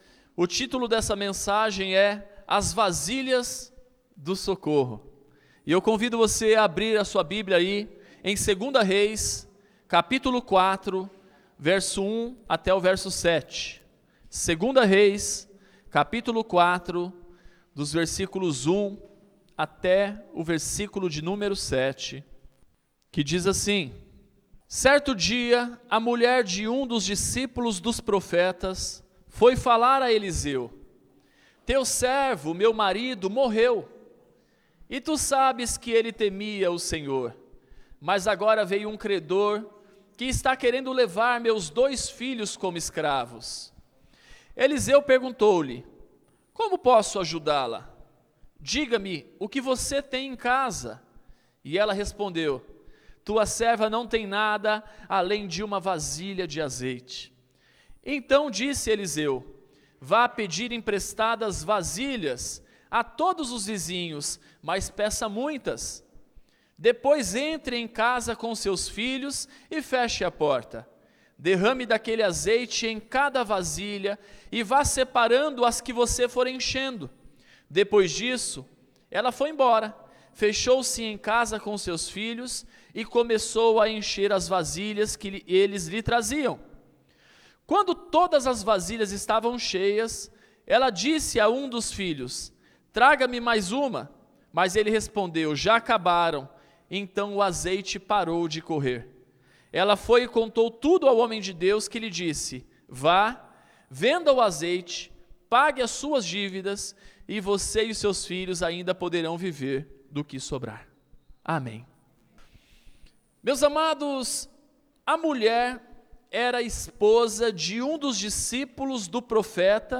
Este episódio pode ter algumas mudanças de áudio devido a problemas técnicos.